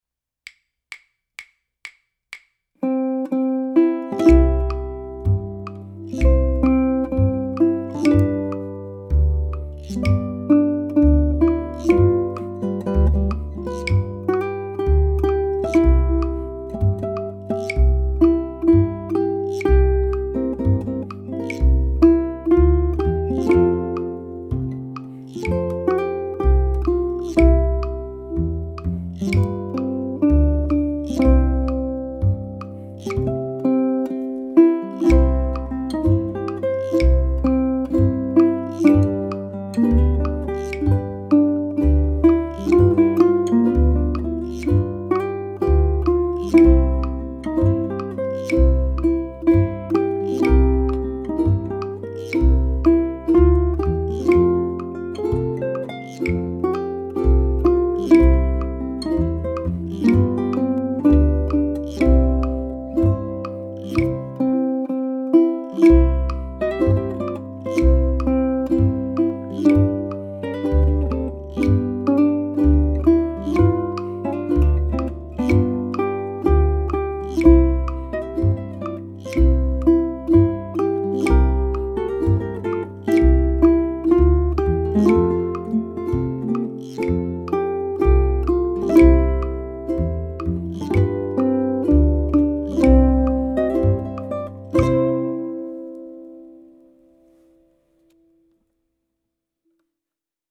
The iconic American folk song, The Banks of the Ohio, has been recorded by Johnny Cash, Dolly Parton, Joan Baez and many others.
This charming but simple melody is based on a four-note rhythmic motive and features notes on the second and third strings.
ʻukulele